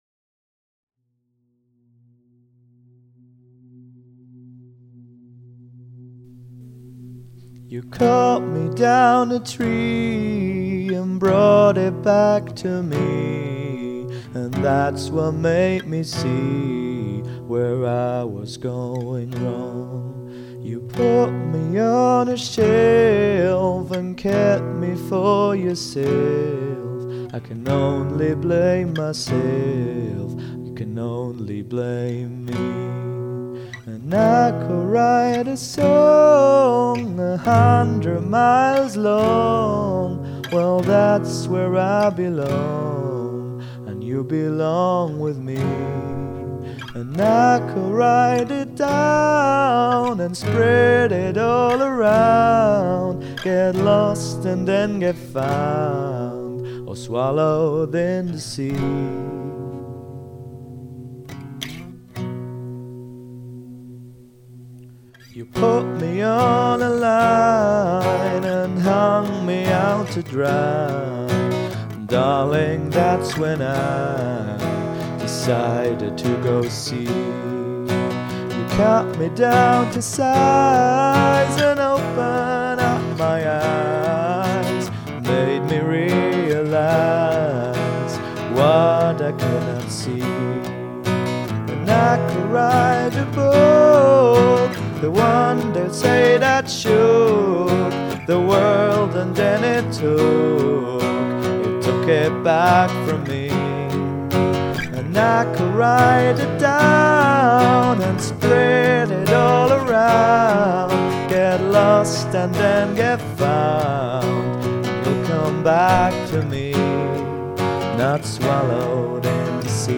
guitar was spot on but it was an eazy piece.
That's why I think it sounds a bit strained at parts.